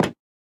Minecraft Version Minecraft Version 1.21.5 Latest Release | Latest Snapshot 1.21.5 / assets / minecraft / sounds / block / cherrywood_trapdoor / toggle3.ogg Compare With Compare With Latest Release | Latest Snapshot